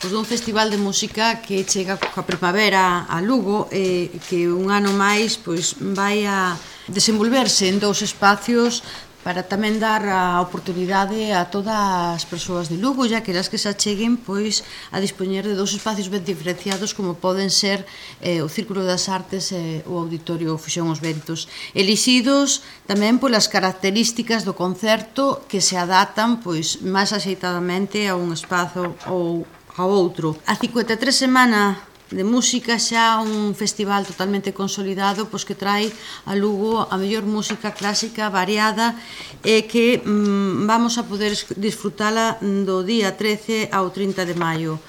• A concelleira de Cultura, Turismo e Promoción da Lingua, Maite Ferreiro, sobre a Semana da Música |